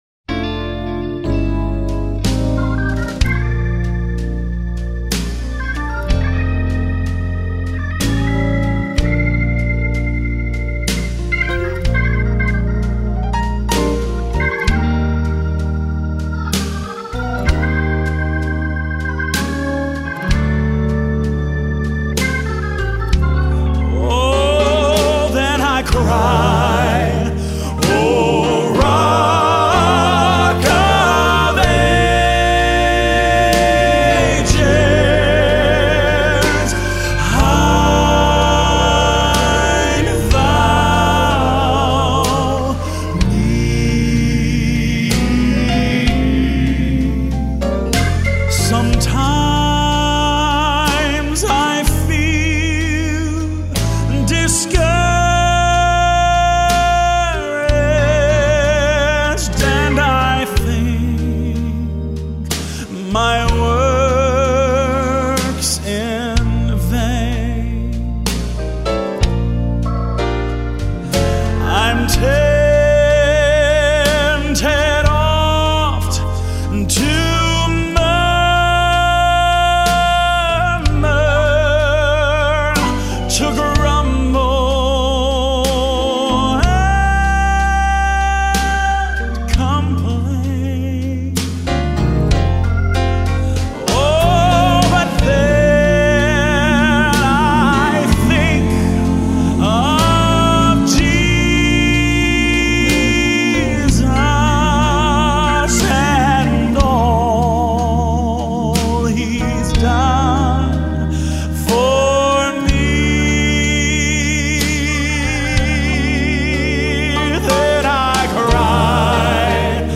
Nosso hino de hoje lembra essa metáfora bíblica.